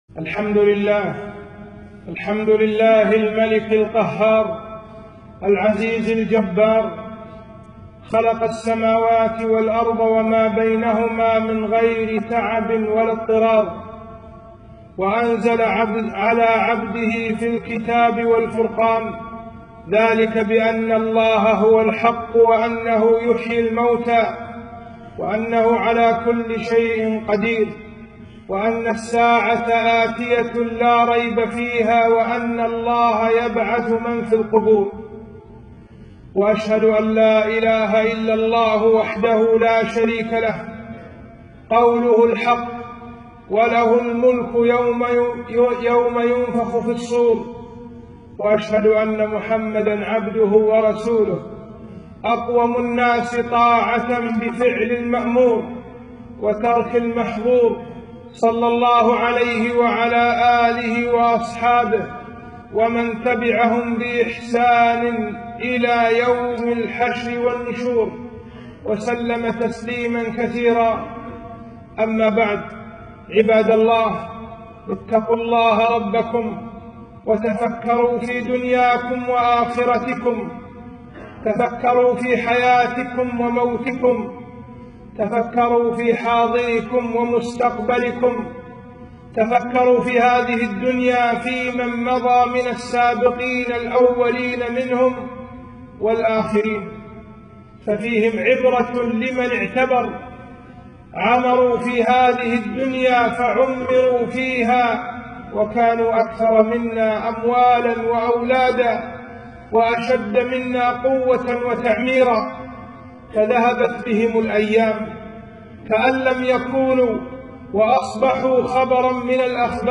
خطبة - أهوال يوم القيامة